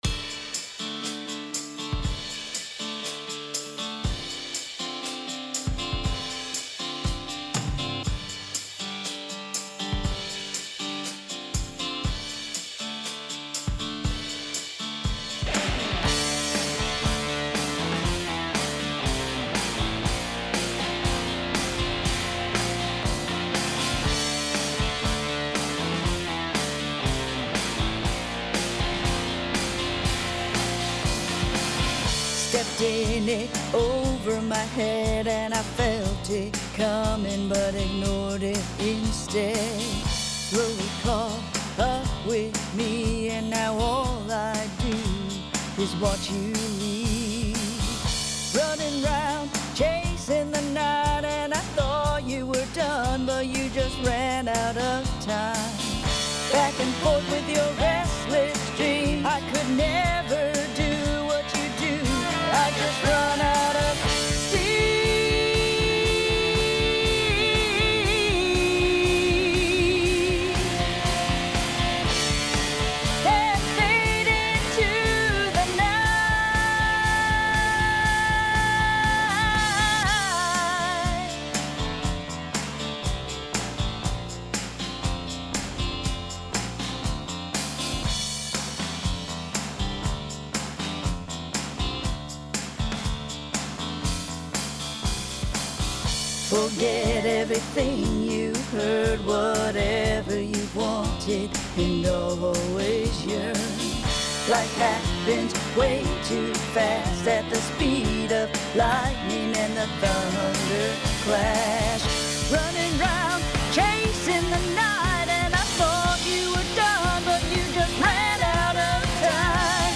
Vocals
Drums